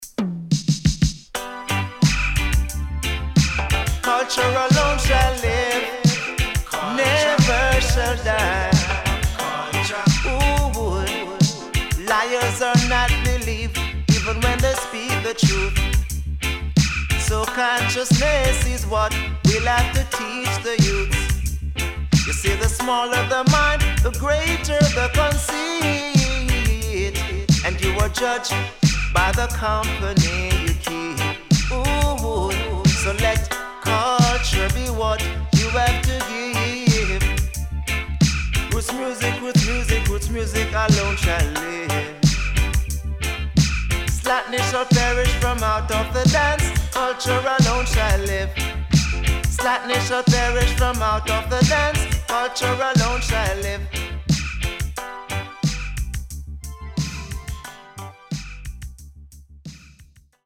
【12inch】
SIDE AA:盤質は良好です。